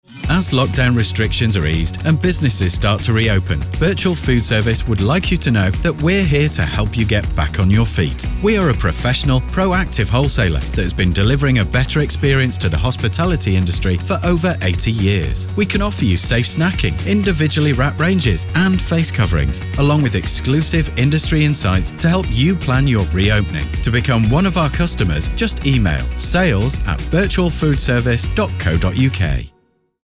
Last month, we launched our first ever radio campaign!